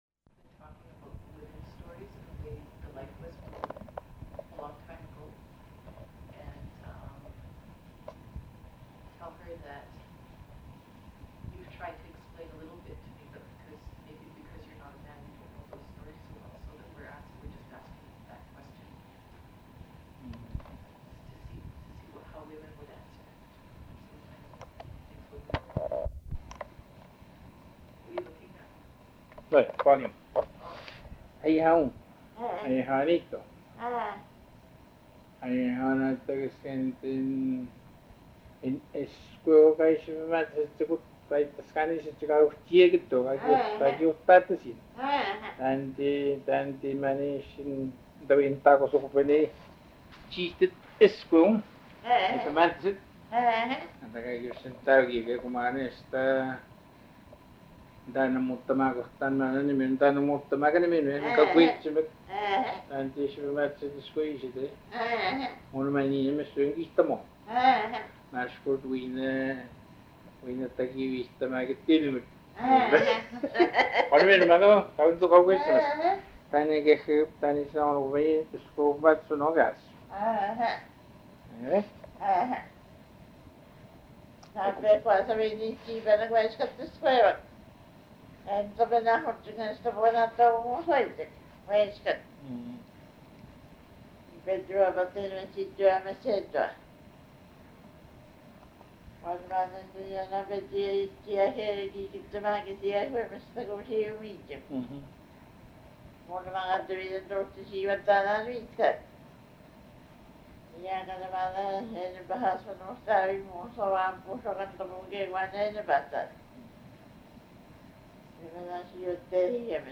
Interview
(Restored)